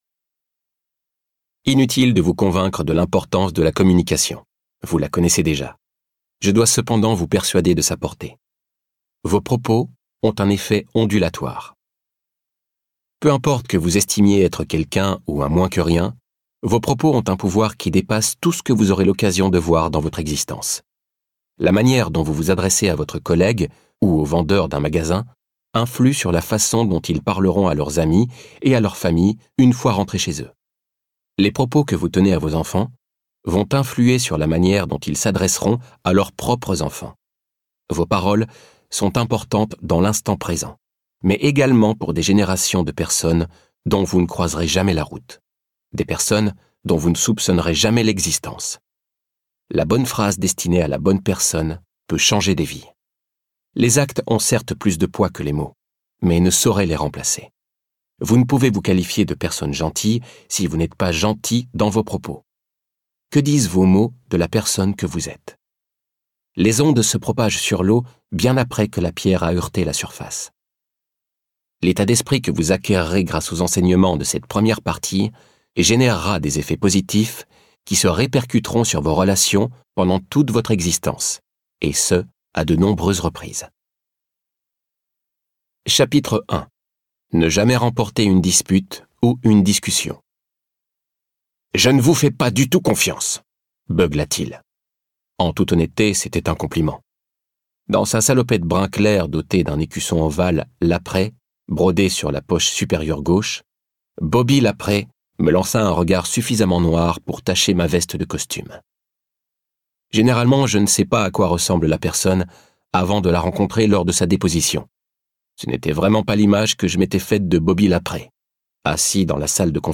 Le livre audio pour faire de votre prochaine conversation celle qui changera toutNous avons tous en tête une conversation que nous devrions avoir, mais que nous repoussons ou évitons, avec notre conjoint, notre chef, notre voisin...